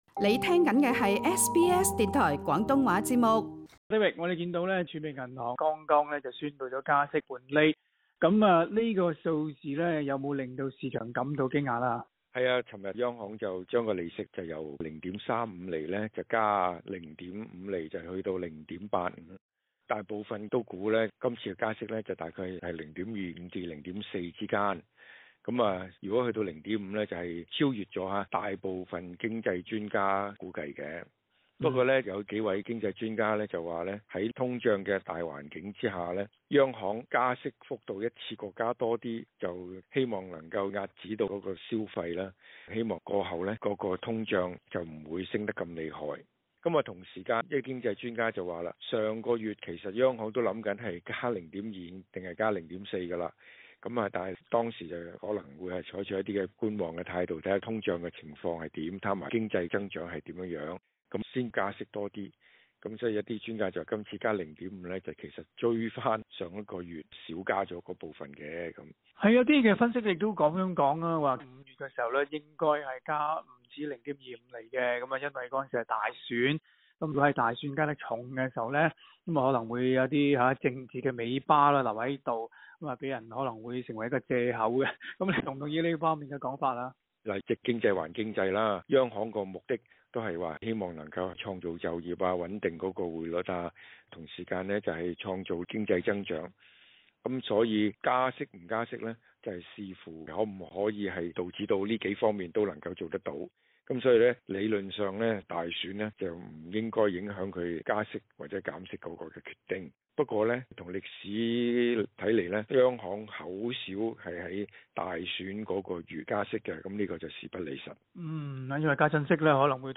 （详情请收听今日的访谈内容） 上证综指周二在亚太区得天独厚，取得0.17%的进账，收于两个月的高位 3,241.76点，原因与中国放宽新冠疫情限制，因而提振了投资者的信心。